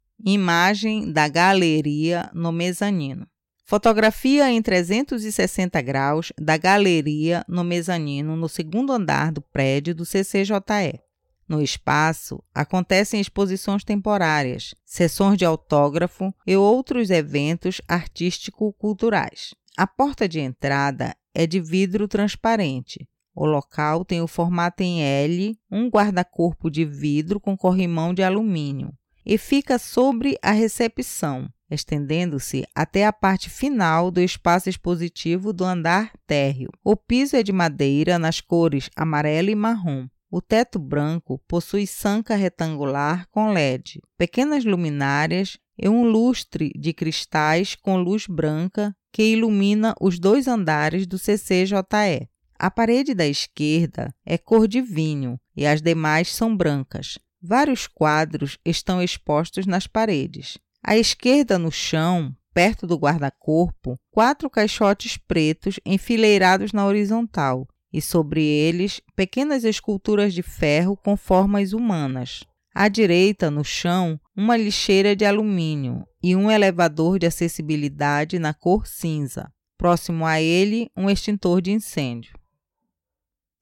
Galeria do Mezanino audiodescrição